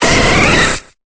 Cri de Voltali dans Pokémon Épée et Bouclier.